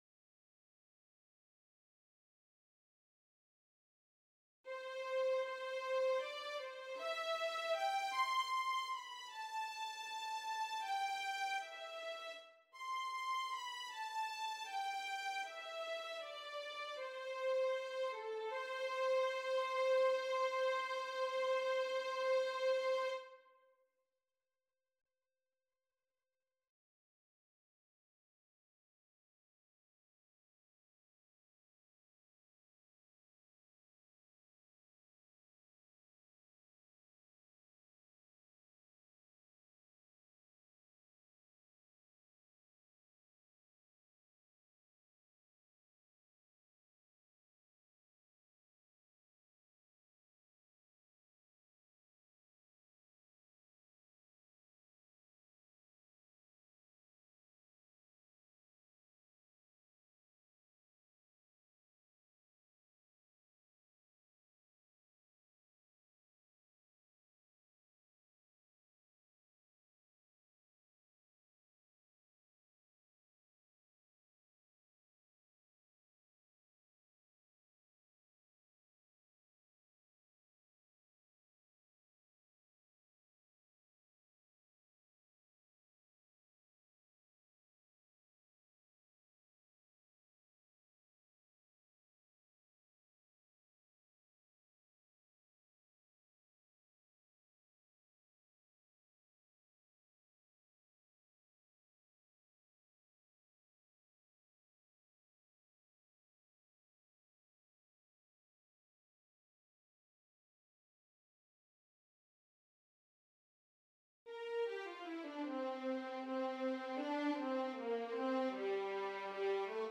Early Advanced Piano and Violin; Voice Parts Easy